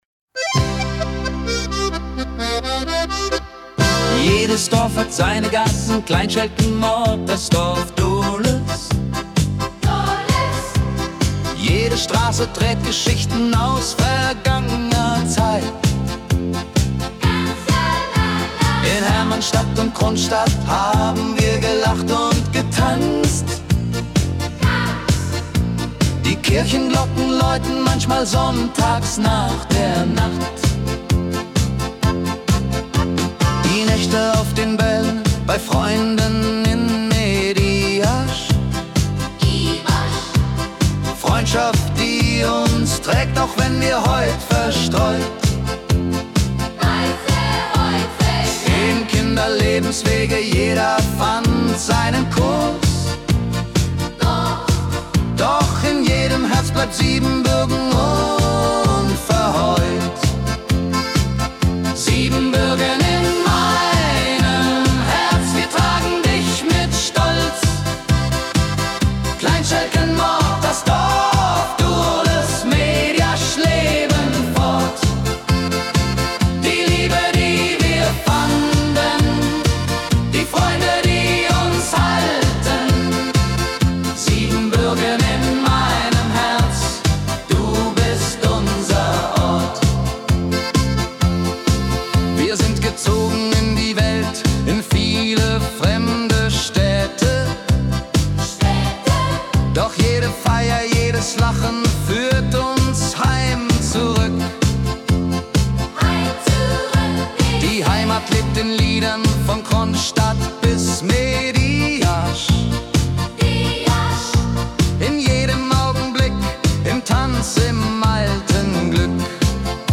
Volks-Hit